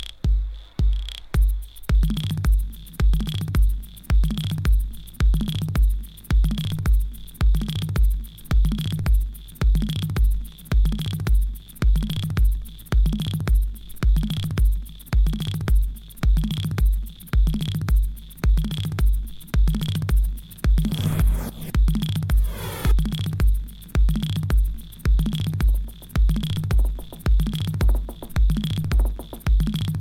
a música aqui é alienígena e minimalista